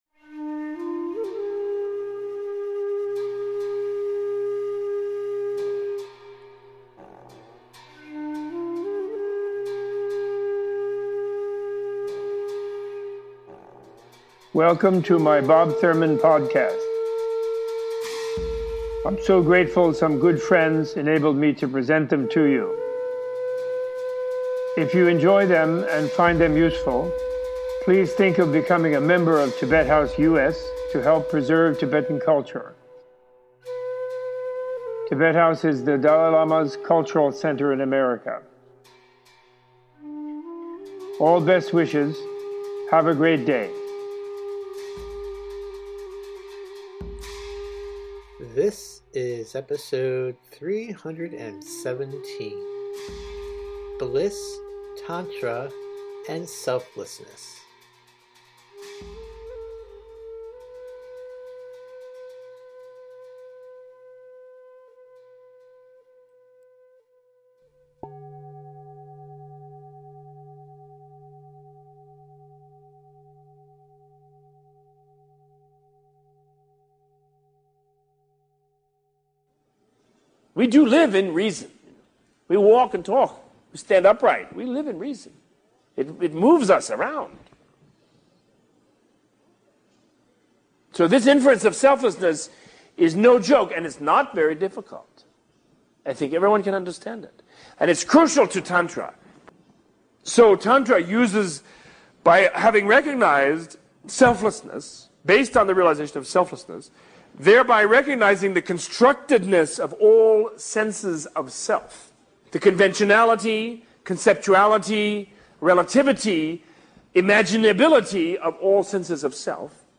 -Text From Better Listen Basic Buddhism This episode is an excerpt from the Better Listen “Basic Buddhism” Audio Course.